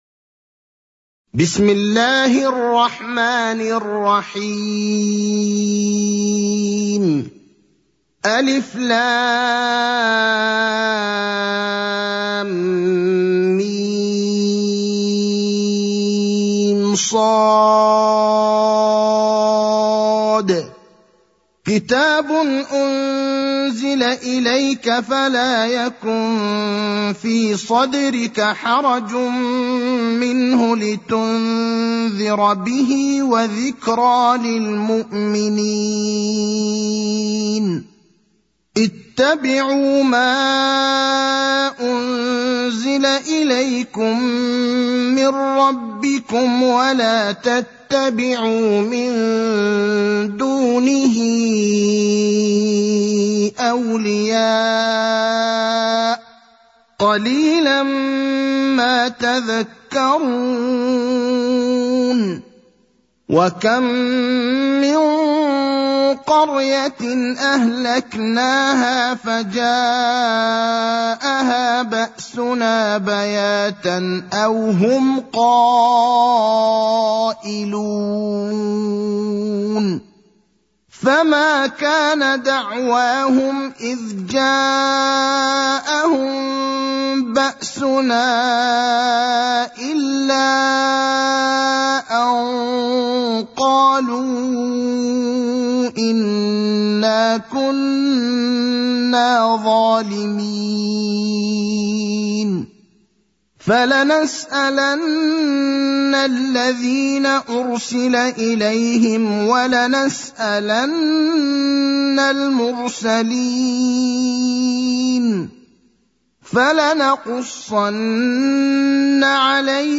المكان: المسجد النبوي الشيخ: فضيلة الشيخ إبراهيم الأخضر فضيلة الشيخ إبراهيم الأخضر الأعراف (7)